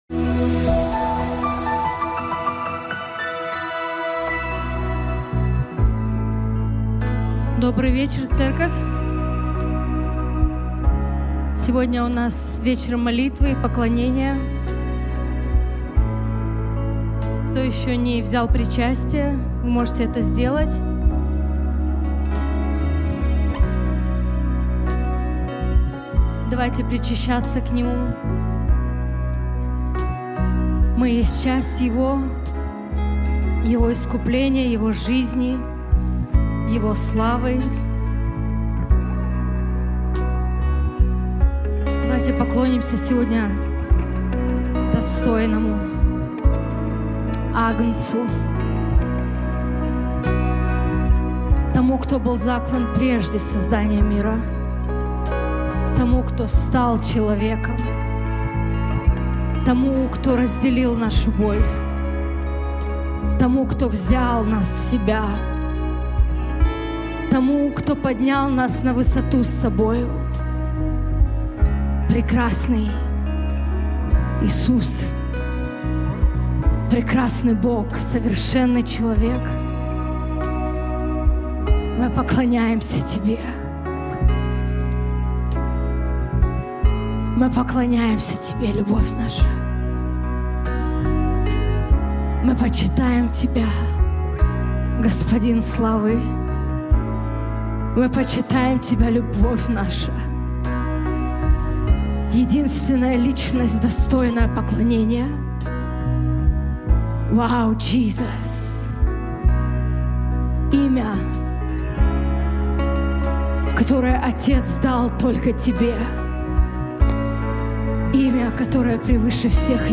Molitva_819.mp3